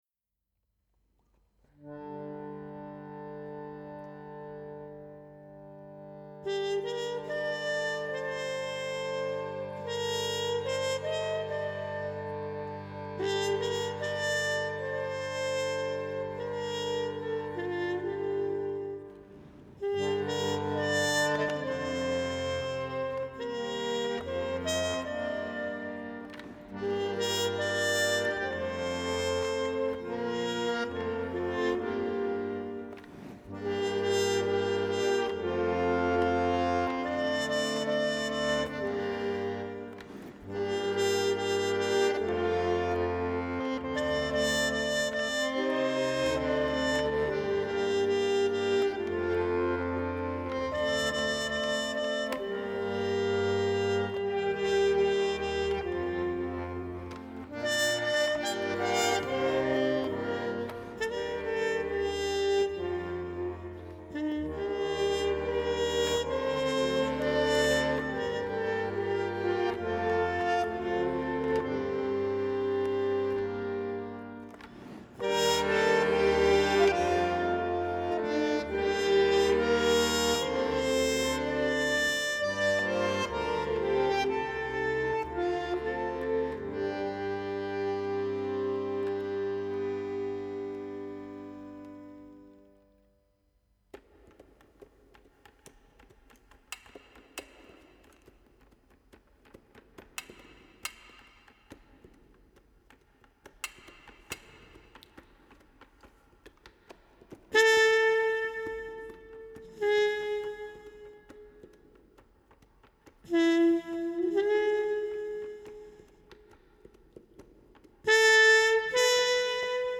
Жанр: Jazz.